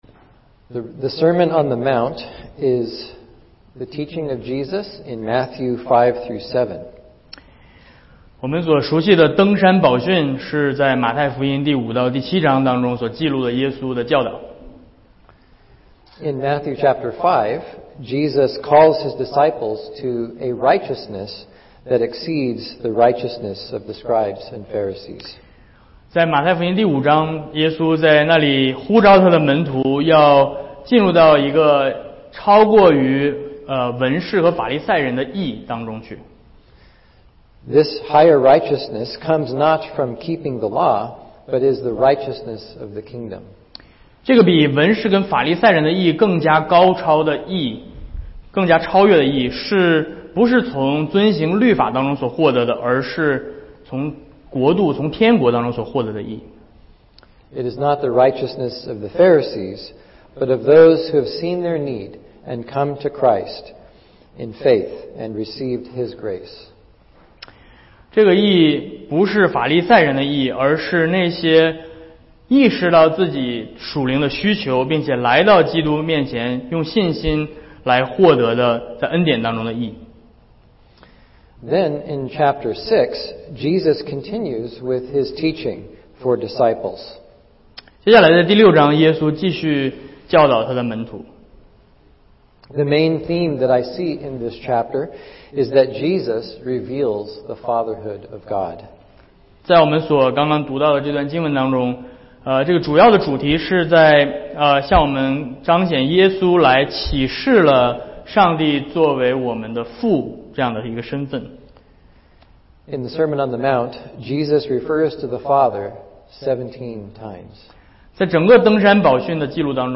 Matthew6:1-18 Service Type: 主日讲道 « 撒种的比喻（马可福音4:1-20）